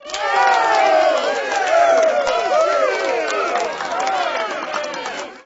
crowd_cheershort.wav